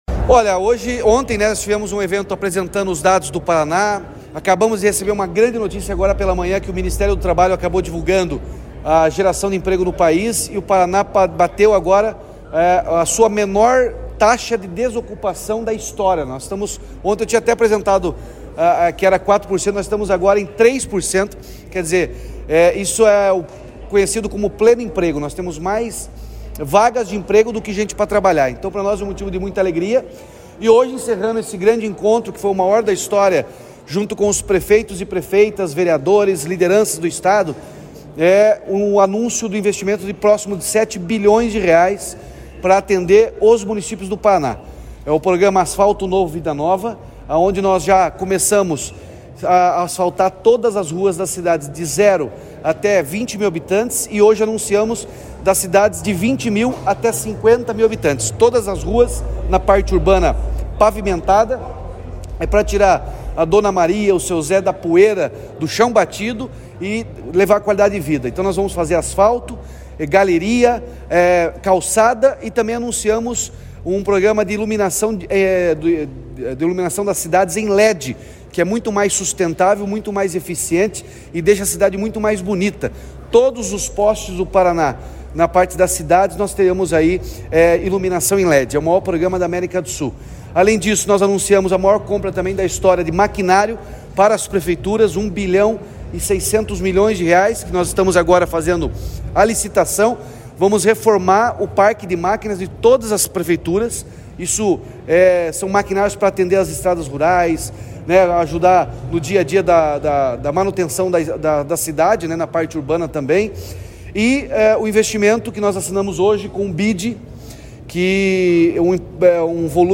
Sonora do governador Ratinho Junior sobre o anúncio de pacote de R$ 6 bilhões de investimentos nos municípios paranaenses